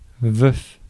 Ääntäminen
Ääntäminen France: IPA: [vœf] Haettu sana löytyi näillä lähdekielillä: ranska Käännös Substantiivit 1. lesk Suku: m .